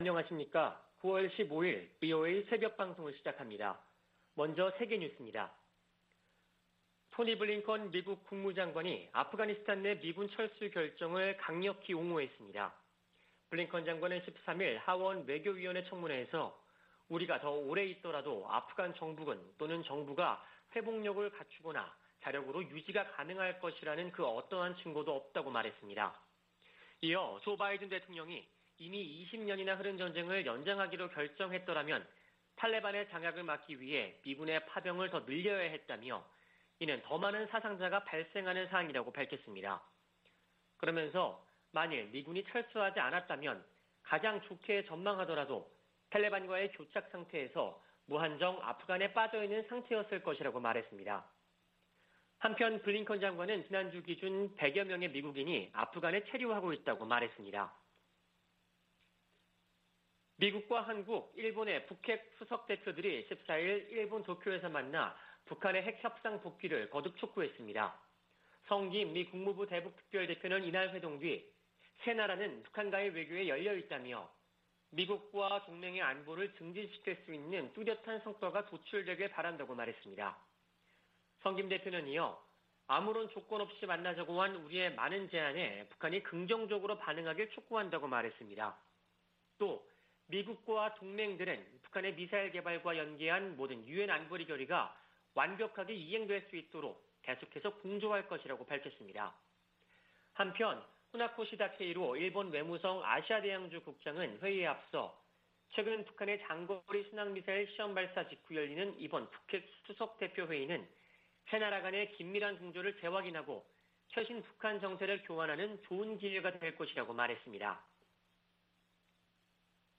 VOA 한국어 '출발 뉴스 쇼', 2021년 9월 15일 방송입니다. 성 김 미국 대북특별대표는 미국은 북한에 적대적 의도가 없다며 북한이 대화 제의에 호응할 것을 거듭 촉구했습니다. 북한의 신형 장거리 순항미사일 시험발사는 도발이라기 보다는 무기체계 강화의 일환이라고 미국의 전문가들이 평가했습니다. 북한의 미사일 발사는 주민들의 식량을 뺏는 행위라고 국제인권단체가 비판했습니다.